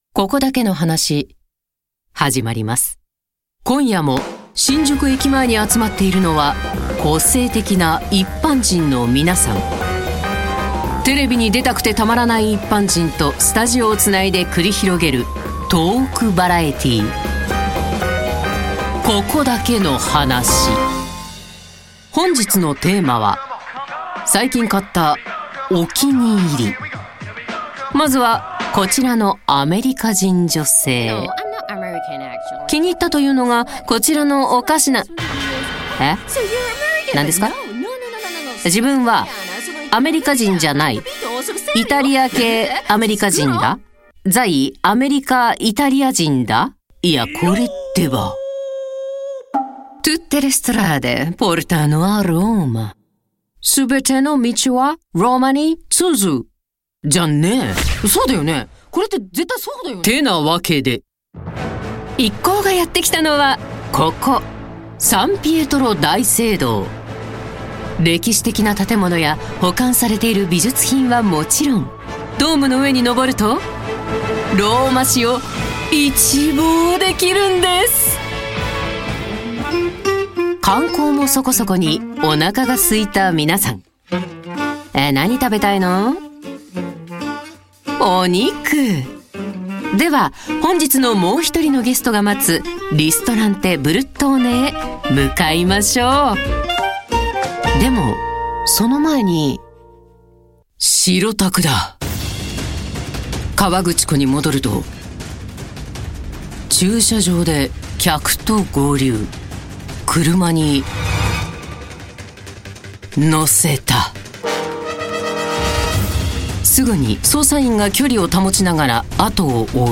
Fernsehshows
Ihre strahlende, tiefe Stimme hat eine ausgeprägte Überzeugungskraft und einen vertrauenerweckenden Klang.